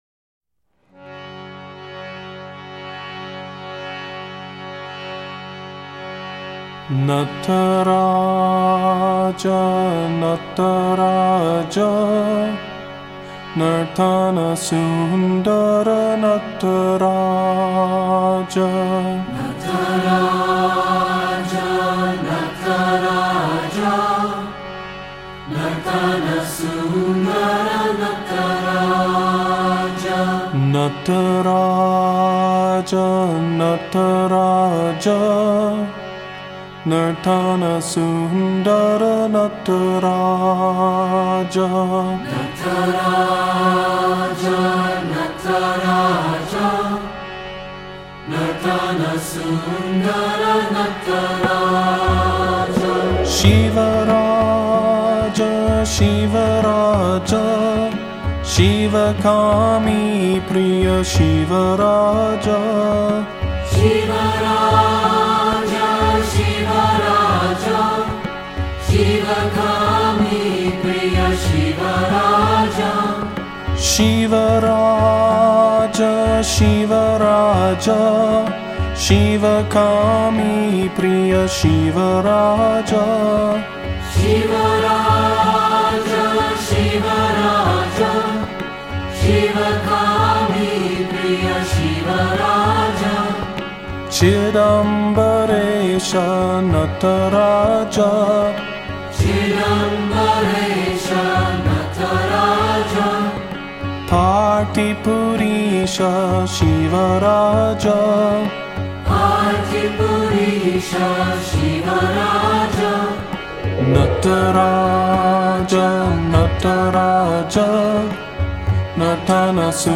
shiva_mantra.mp3